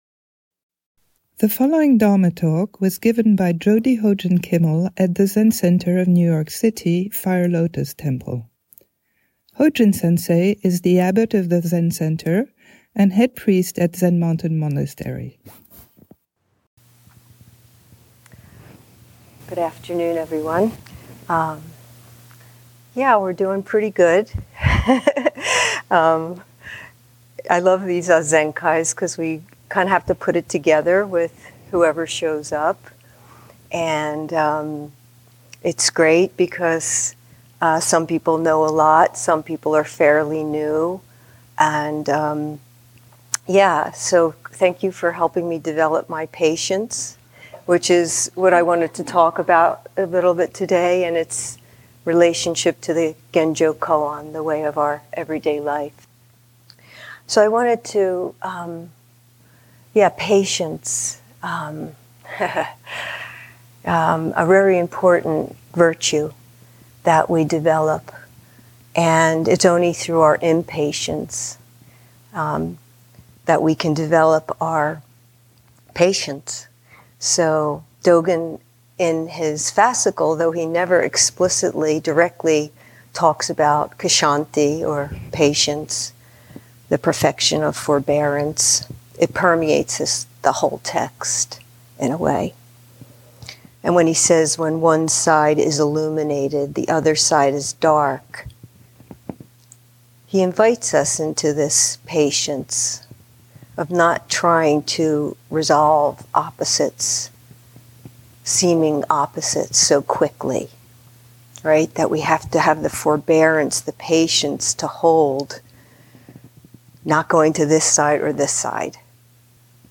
Saturday Zazenkai Talk
at Fire Lotus Temple